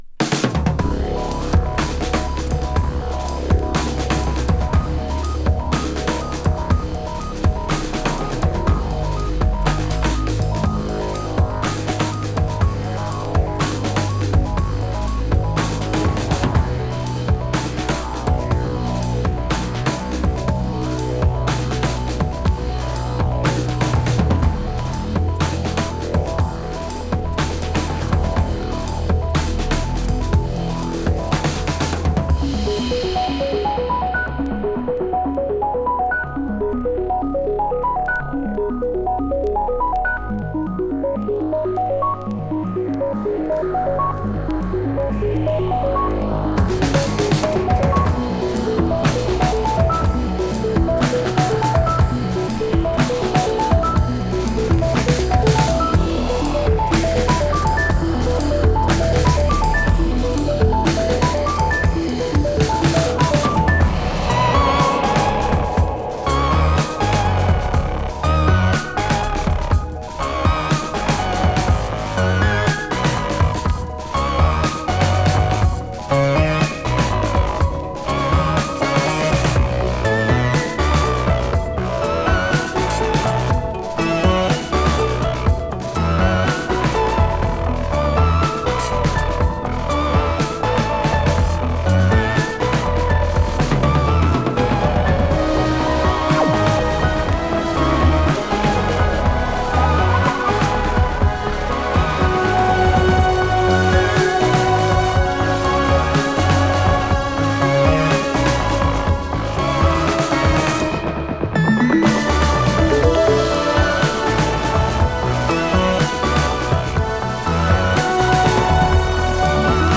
10分近くに渡ってじわじわ壮大に開けていく世界観のエレクトロニック・ブレイクビーツ・ディスコへと料理。